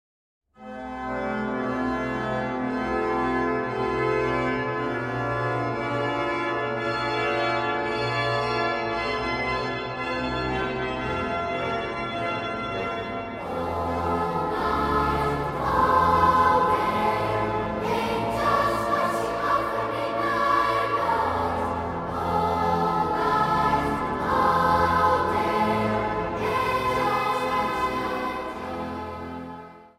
vleugel en orgel.
1 stem
Zang | Kinderkoor